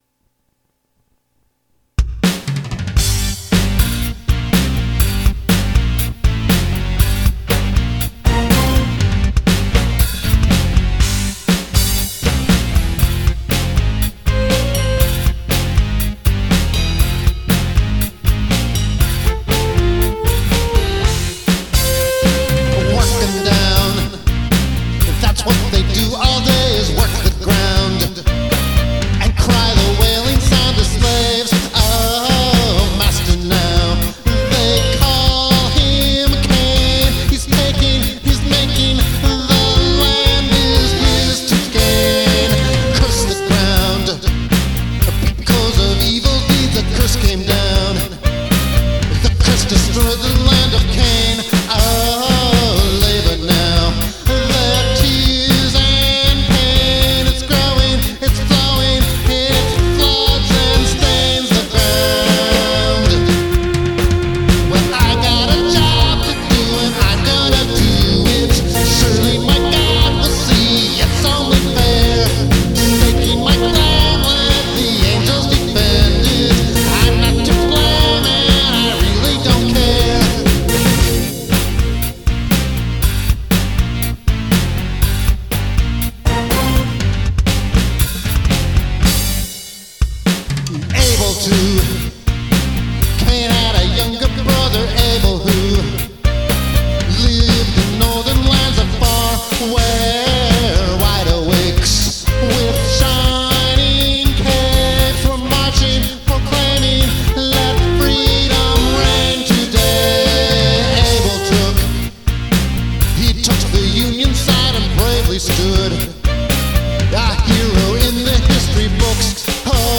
Drum-focused original songs